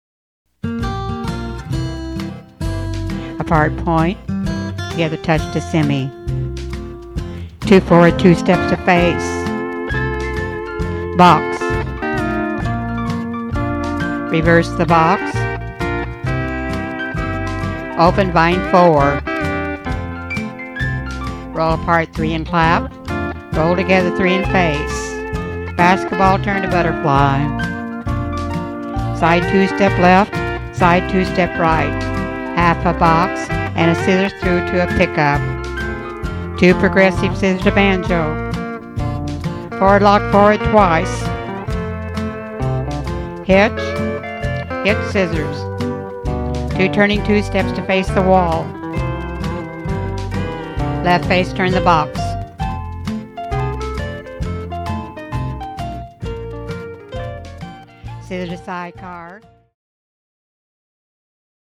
Cued Sample
Two Step, Phase 2+1